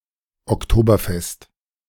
Oktoberfest (German pronunciation: [ɔkˈtoːbɐˌfɛst]
De-Oktoberfest.ogg.mp3